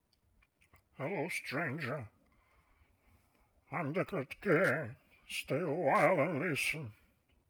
Варианты изменения голоса вы можете видеть на скриншоте.
Записал я звук в WAV для быстроты стандартными средствами Windows.
Village Elder
villageelder.wav